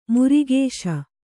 ♪ murigēśa